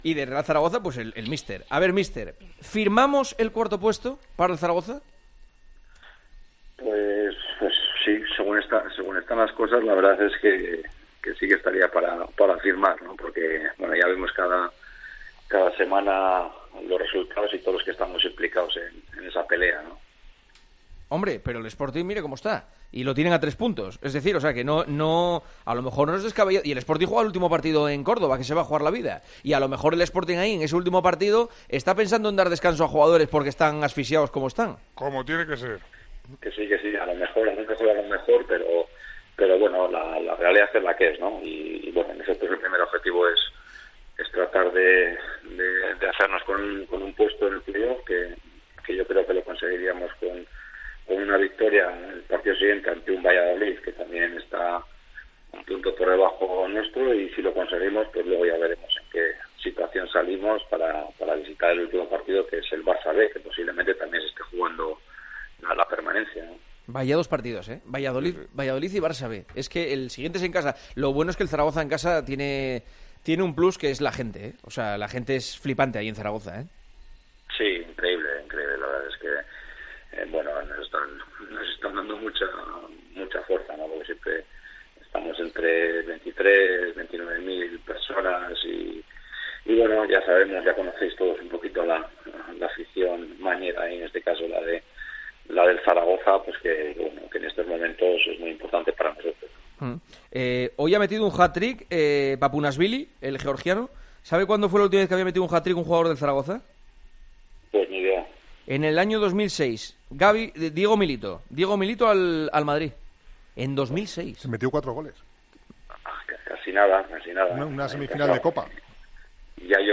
El entrenador del Real Zaragoza habló en Tiempo de Juego a dos jornadas para la conclusión de LaLiga 1|2|3: "Según están las cosas firmamos el 4º puesto.